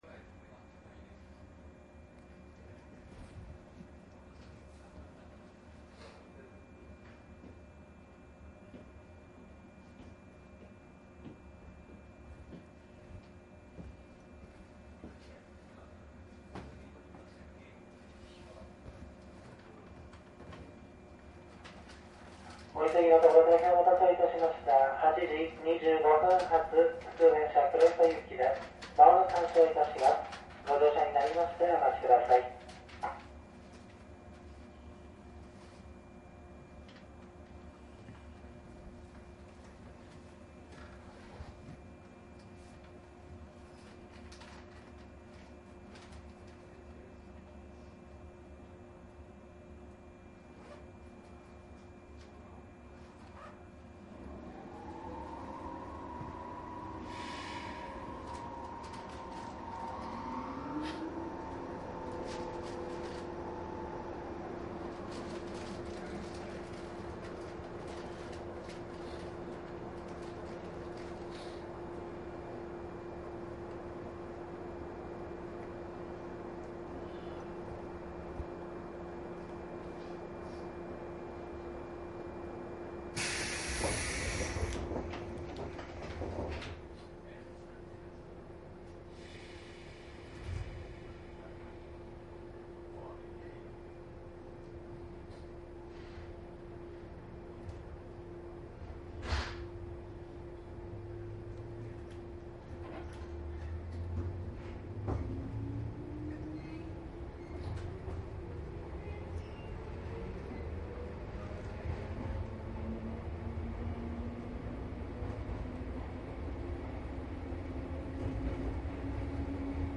JR宇都宮線 115系 走行音  ＣＤ♪
JR宇都宮線 115系で走行音を録音したCDです
■【普通】宇都宮→黒磯 モハ115－121＜DATE02-3-10＞
DATかMDの通常SPモードで録音（マイクＥＣＭ959）で、これを編集ソフトでＣＤに焼いたものです。